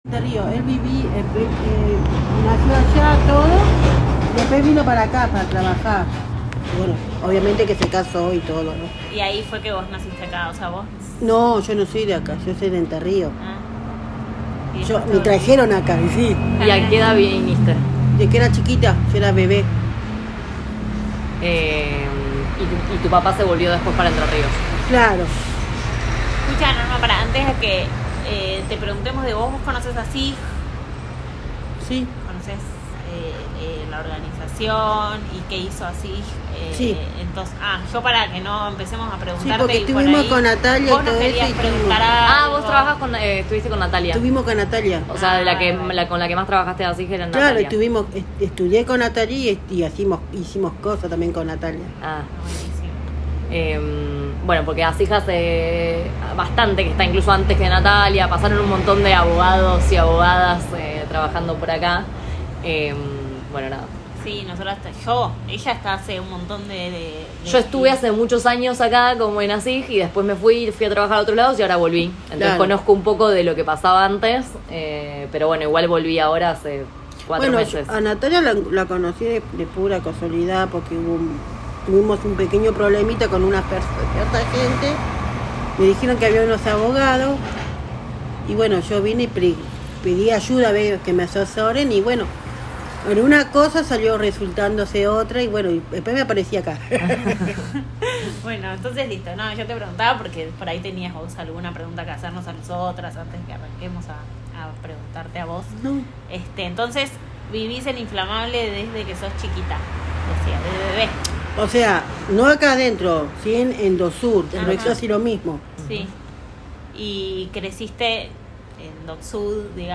1 grabación sonora en soporte magnético